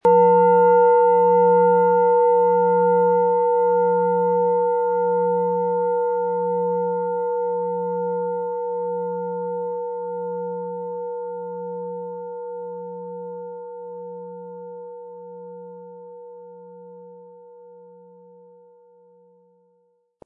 Tibetische Universal-Klangschale, Ø 18,1 cm, 700-800 Gramm, mit Klöppel
Im Lieferumfang enthalten ist ein Schlegel, der die Schale wohlklingend und harmonisch zum Klingen und Schwingen bringt.
MaterialBronze